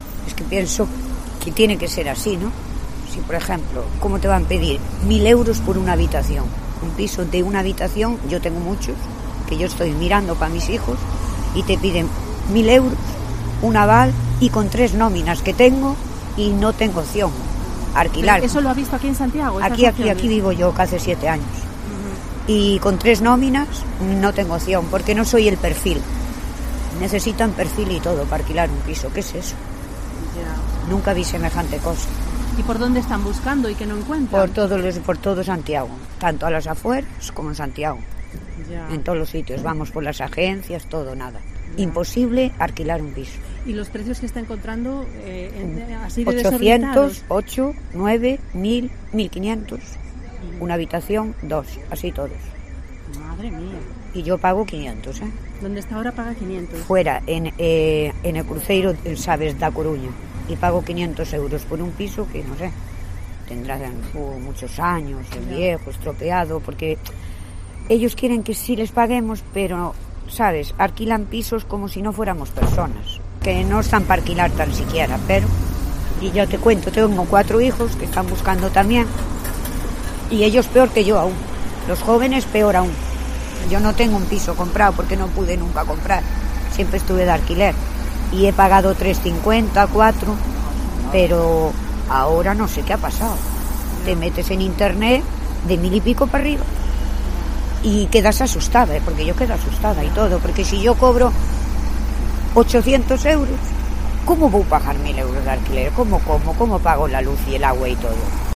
Una vecina de Santiago relata sus problemas para encontrar piso de alquiler